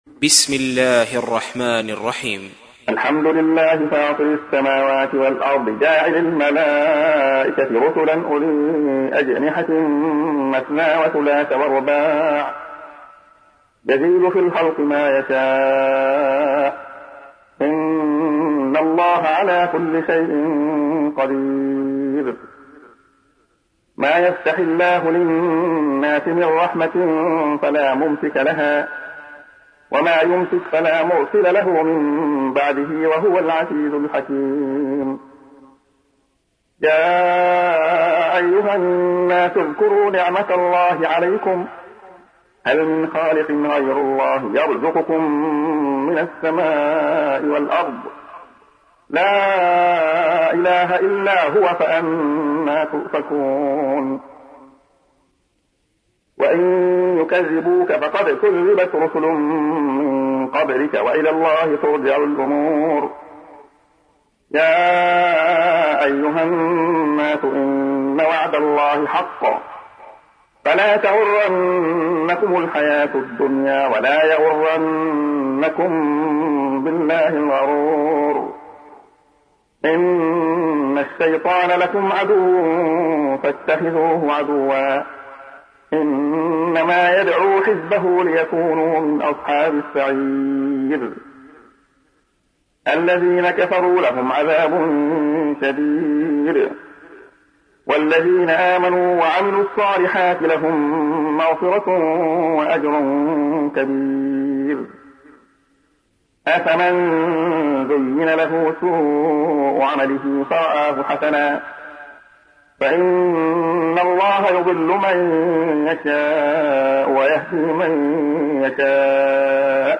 تحميل : 35. سورة فاطر / القارئ عبد الله خياط / القرآن الكريم / موقع يا حسين